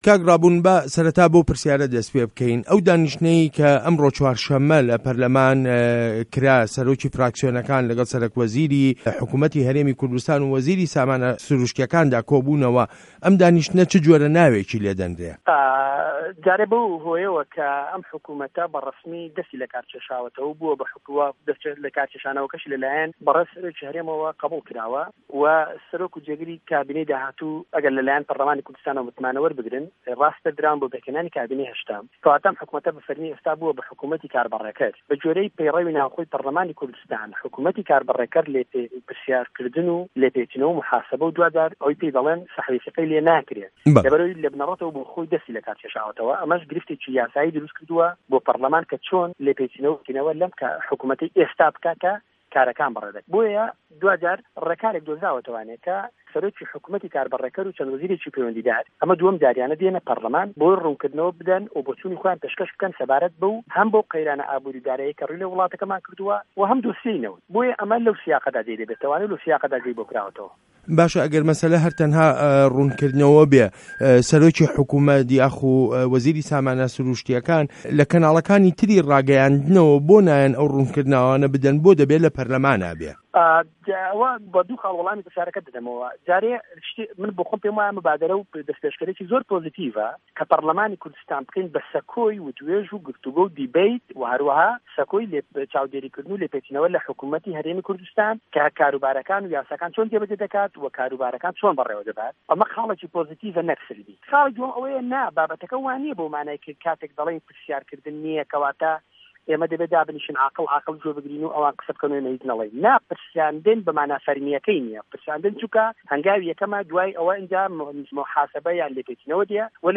وتووێژ له‌گه‌ڵ ڕابوون مه‌عروف